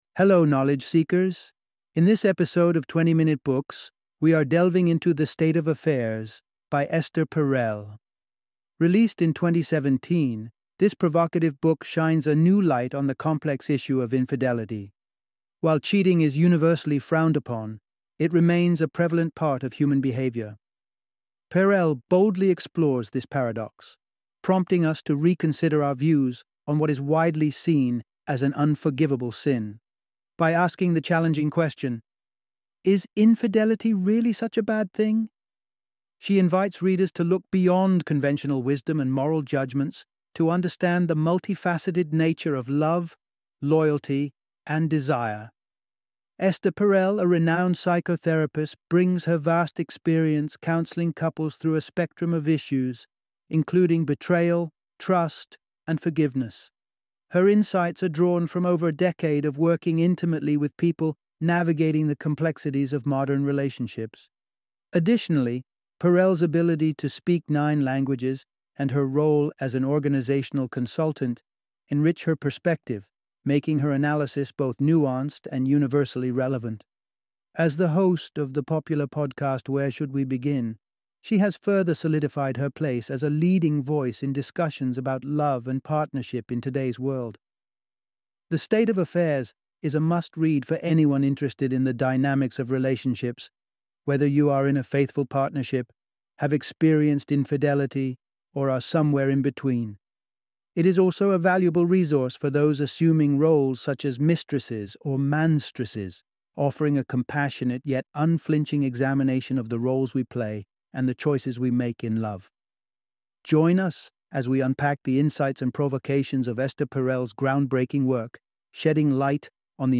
The State of Affairs - Audiobook Summary